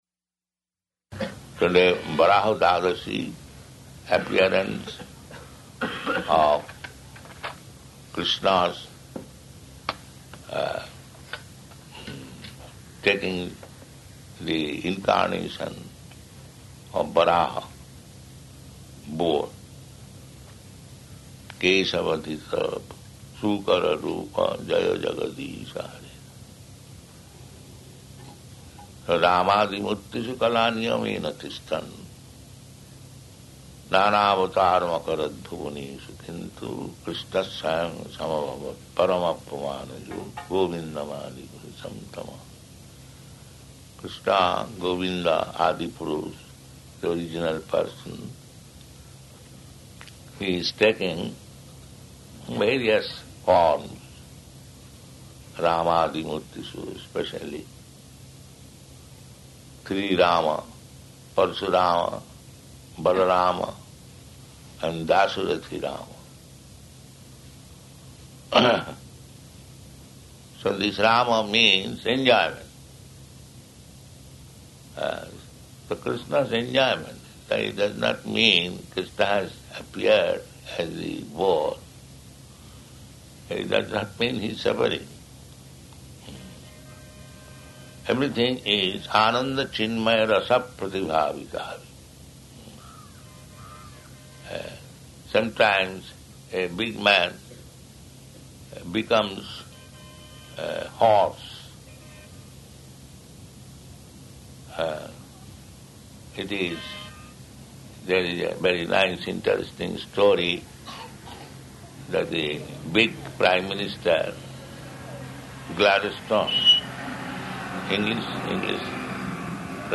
Varāha-dvadaśī, Lord Varāha's Appearance Day Lecture